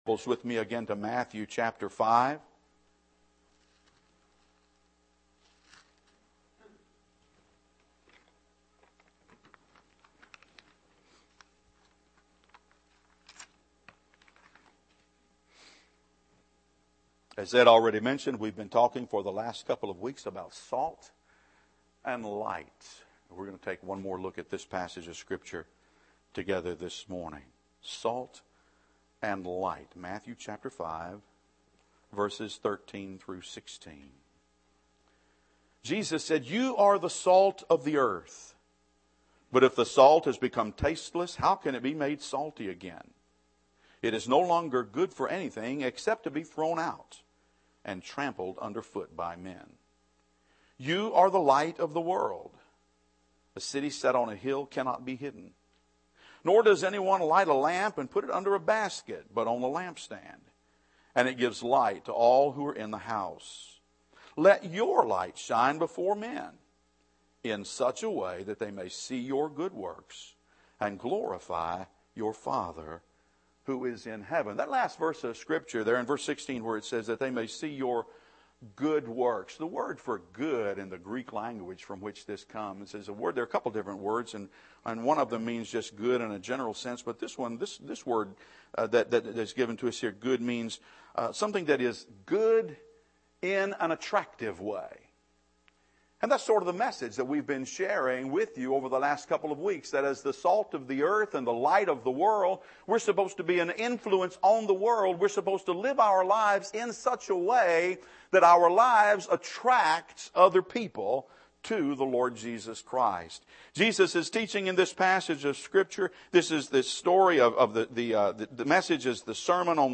Teaching Series: Salt and Light